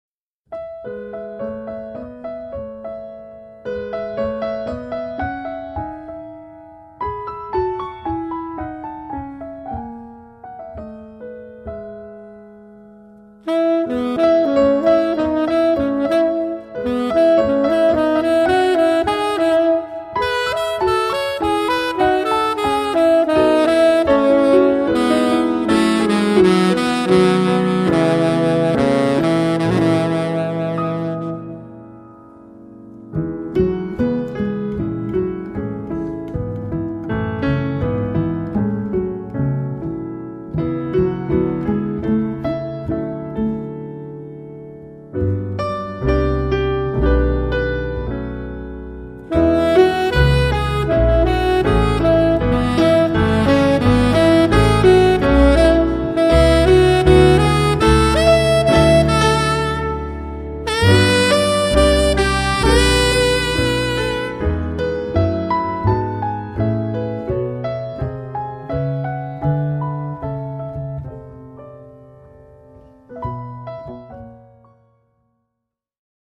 sax tenore & soprano
pianoforte
contrabbasso